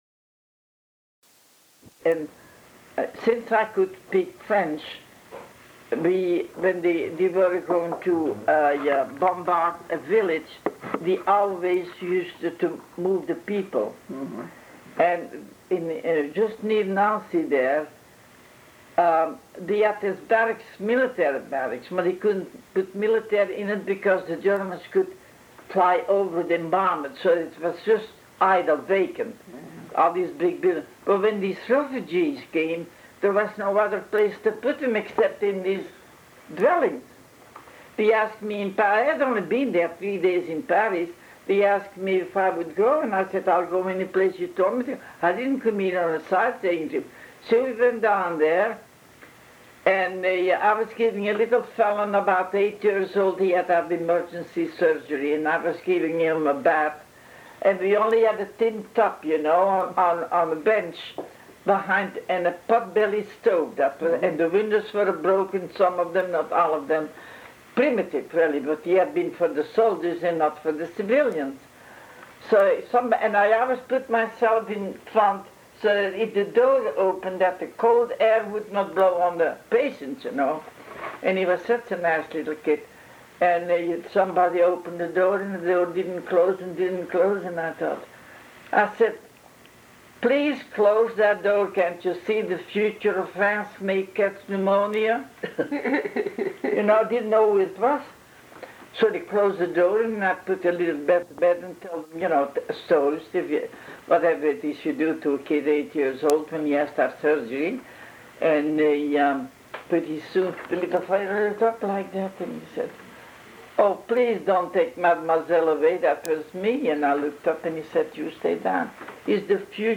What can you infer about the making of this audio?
Ends abruptly.